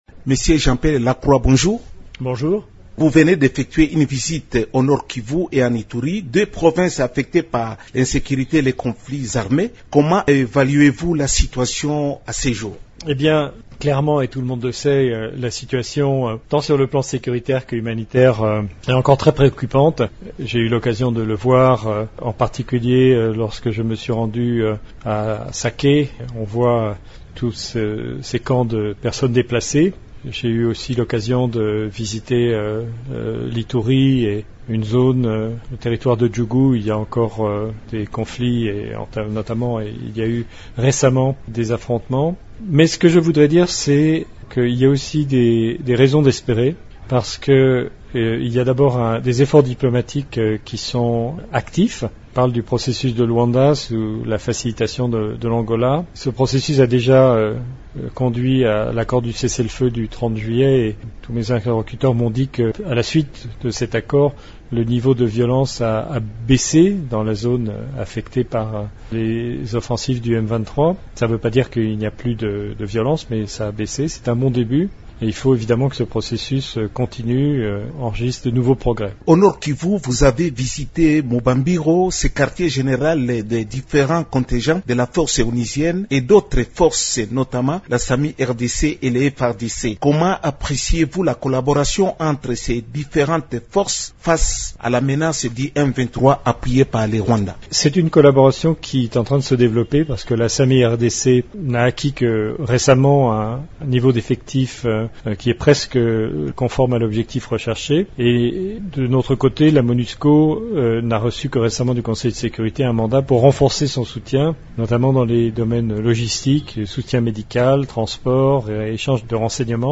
Dans une interview exclusive accordée à Radio Okapi, il a affirmé avoir ressenti cette situation particulièrement dans la cité de Sake, proche de la ville de Goma, au Nord-Kivu.